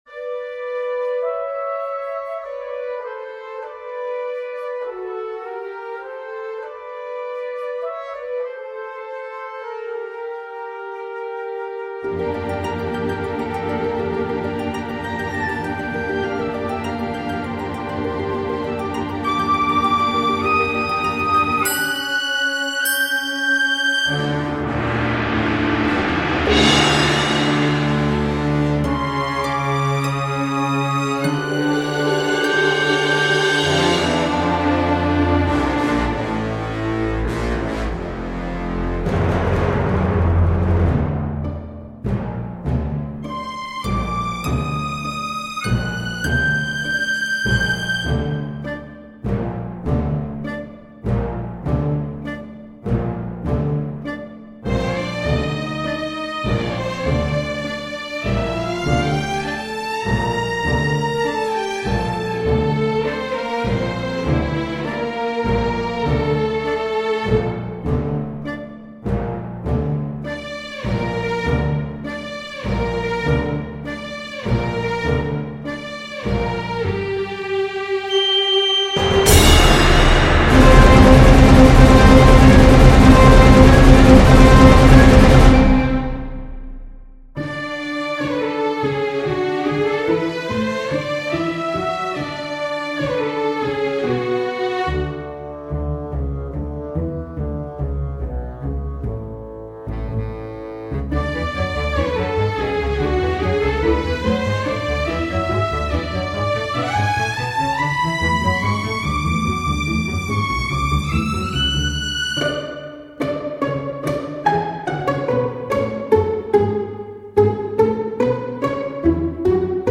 anyways, this is a tone poem that is performed like any other concert piece.